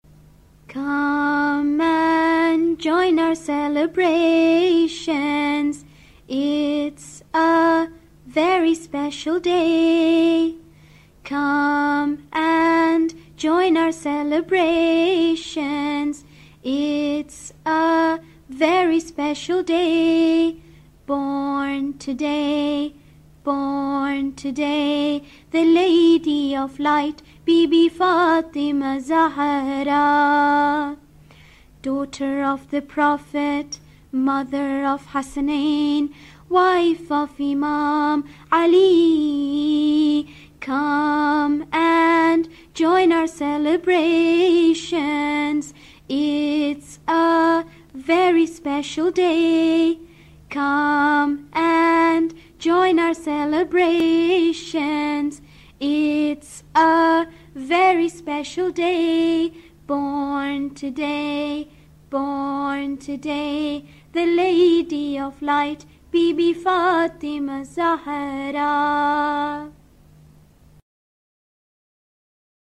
Part of a series of Muslim rhymes for children.